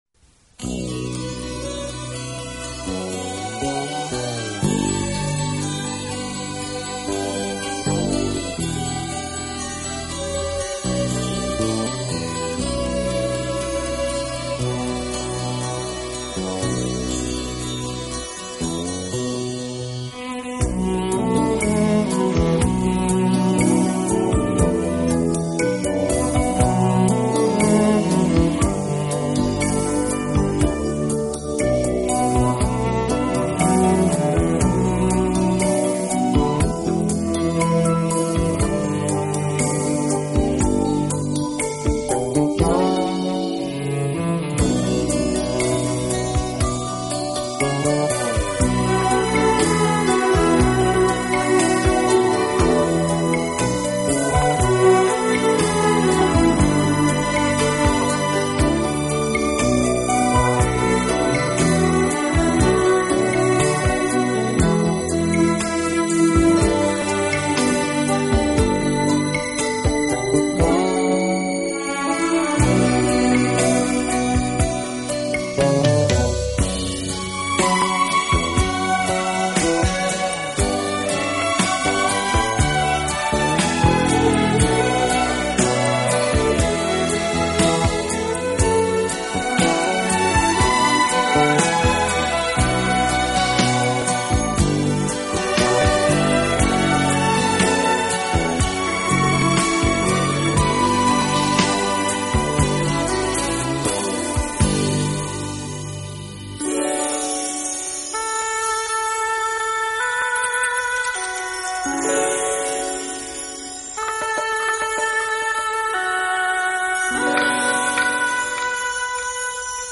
【顶级轻音乐】
这张专辑所选曲目大都是热门流行音乐，在配器上强调清脆的高音和结
实的打击乐，使得整部专辑节奏感十分强烈，加上此专辑的原版磁带录